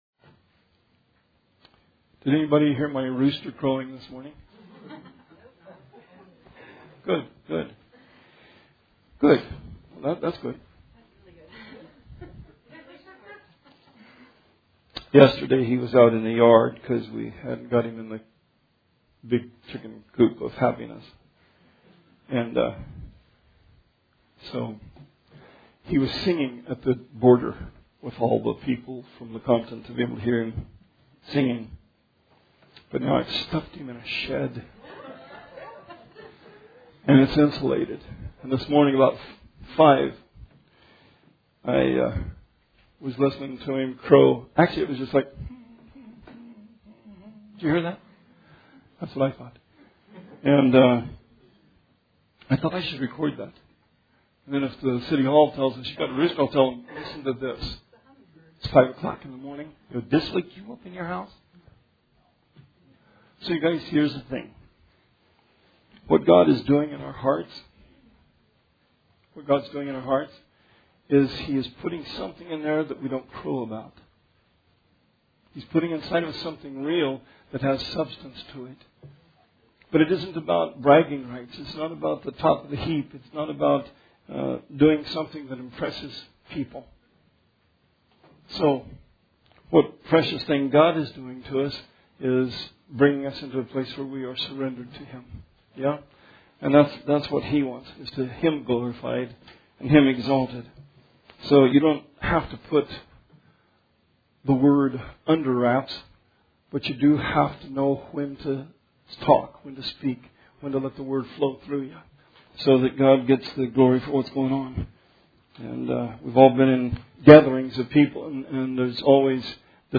Sermon 8/15/20